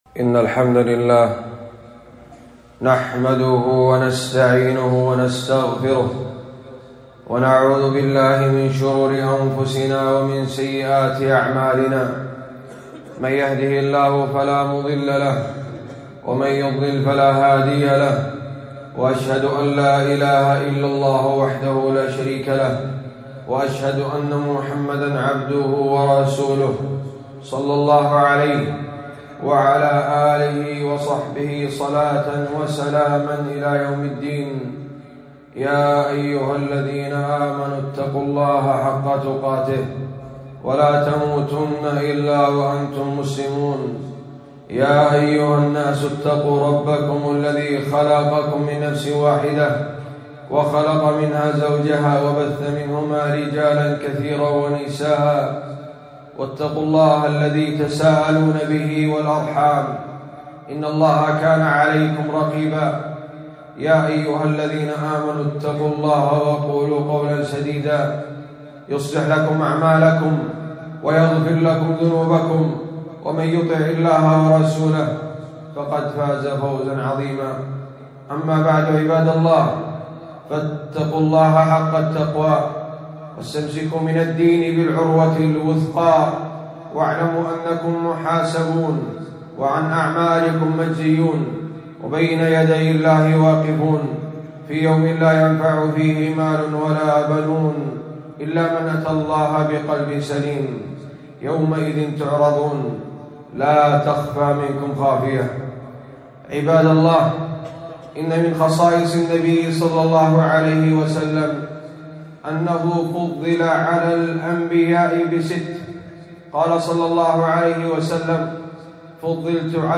خطبة - من وصايا الرسول الجامعة